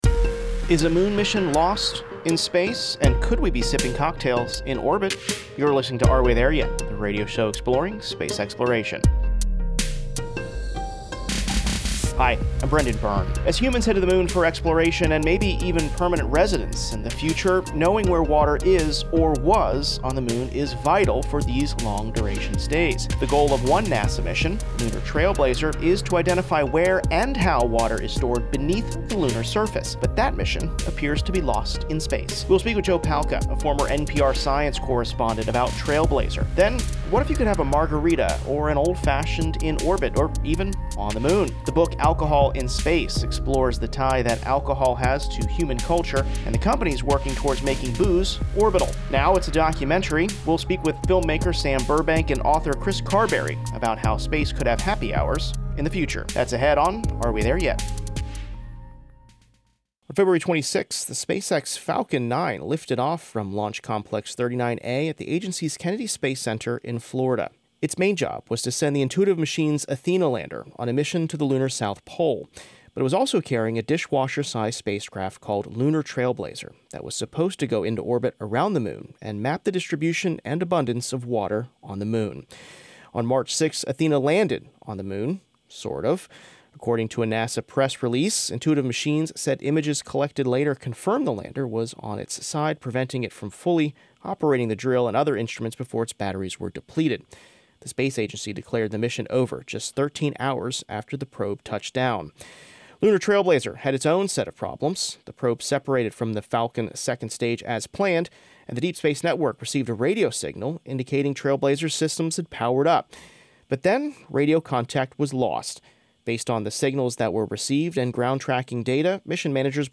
Listen to interviews with astronauts, engineers and visionaries as humanity takes its next giant leap exploring our universe.